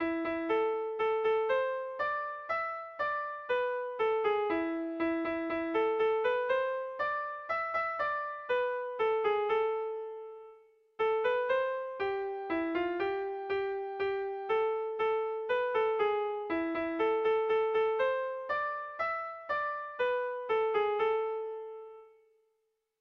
Bertso melodies - View details   To know more about this section
Kontakizunezkoa
A1A2BA2